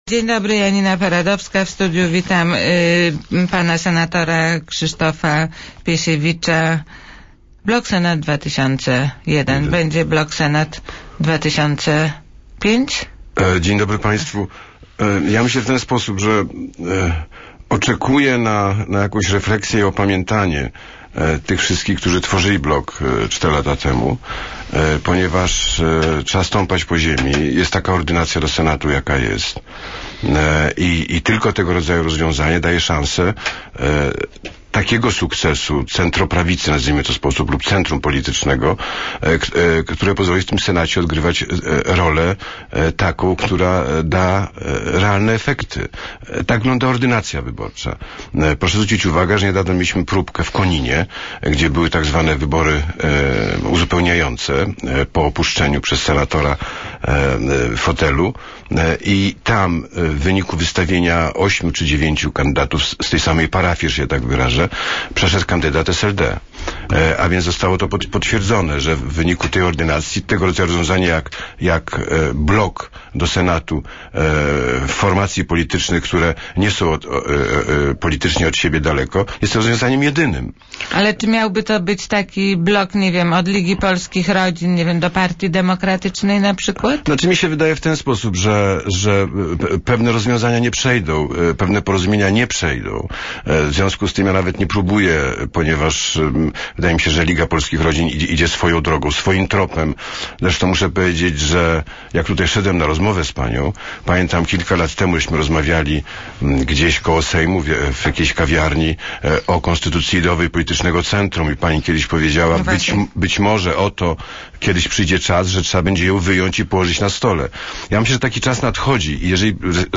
Jeszcze przez miesiąc czekam na możliwość skonstruowania Bloku Senat 2005. Dzisiaj nie chcę deklarować, w jakiej formacji politycznej będę funkcjonował na jesieni, albo czy w ogóle będę funkcjonował w jakiejś formacji politycznej - powiedział Krzysztof Piesiewicz w "Poranku w radiu TOK FM".